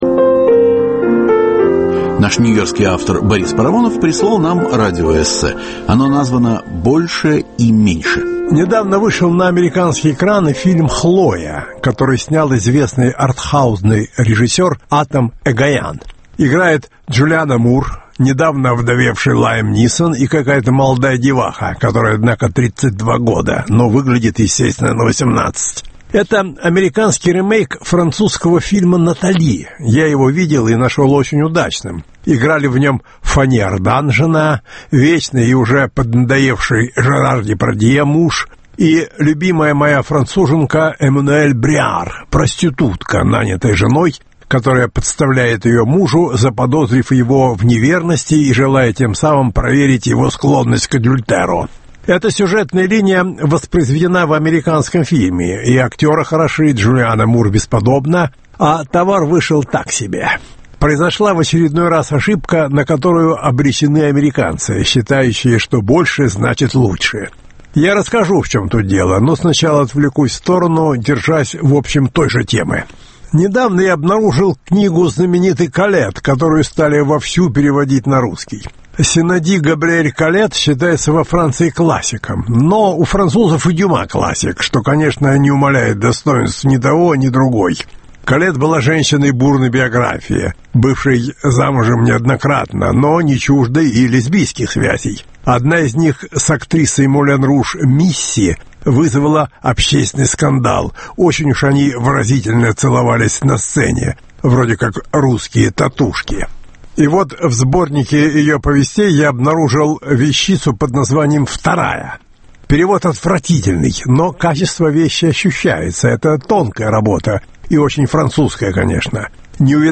Больше и меньше – радиоэссе Бориса Парамонова.